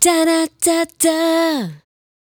Da Da Da Da 156-E.wav